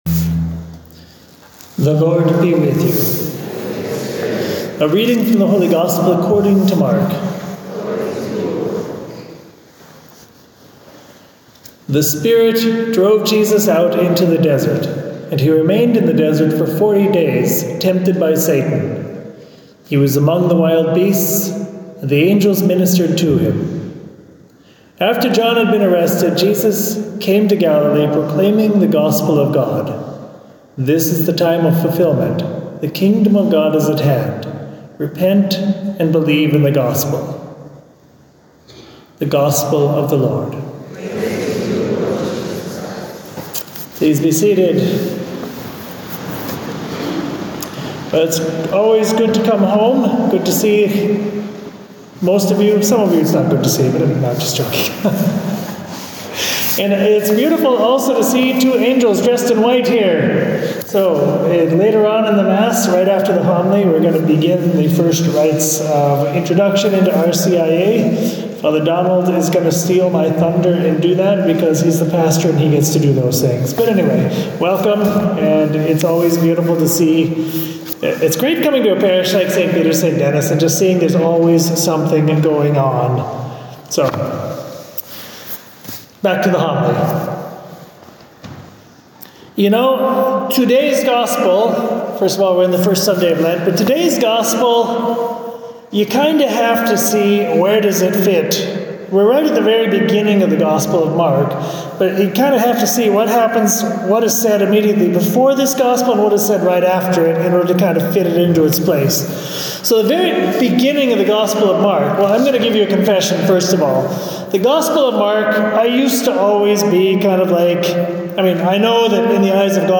Homily
for Sunday, February 18th, at St. Peter’s-St. Denis, Yonkers.